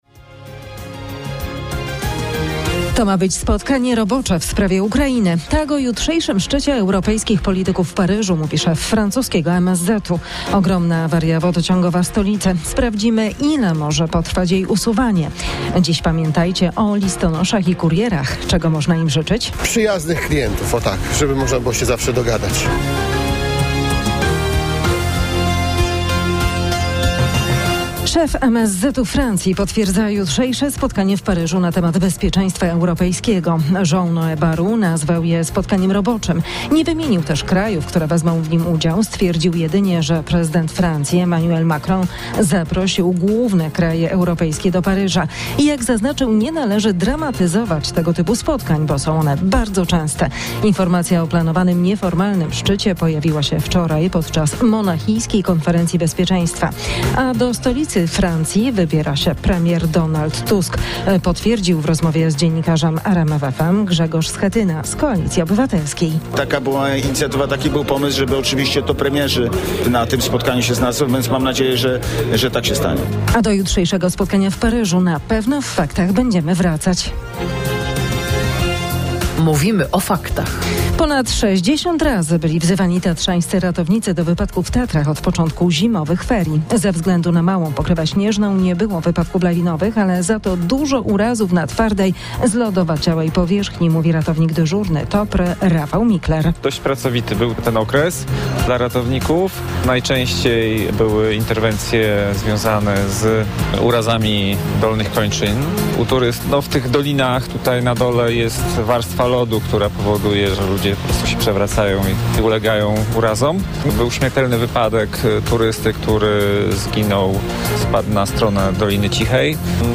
Najświeższe wiadomości z kraju i świata przygotowywane przez dziennikarzy i korespondentów RMF FM. Polityka, społeczeństwo, sport, kultura, ekonomia i nauka.